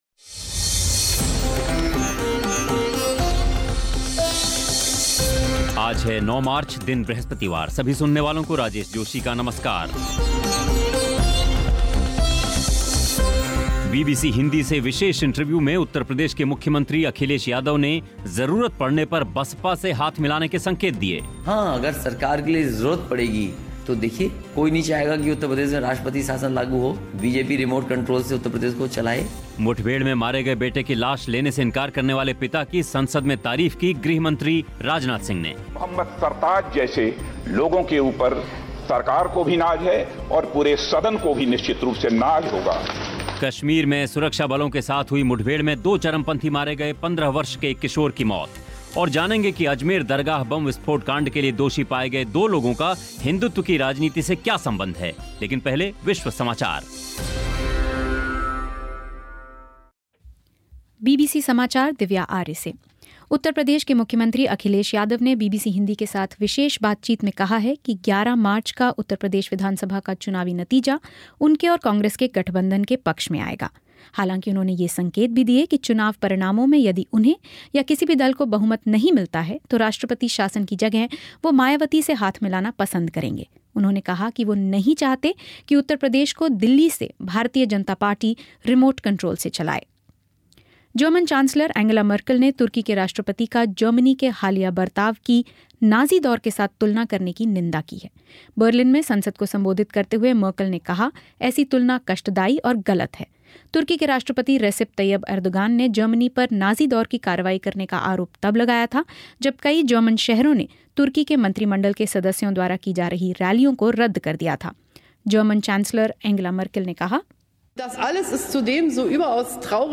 बीबीसी हिंदी से विशेष इंटरव्यू में उत्तर प्रदेश के मुख्यमंत्री अखिलेश यादव ने ज़रूरत पड़ने पर बसपा से हाथ मिलाने के संकेत दिए.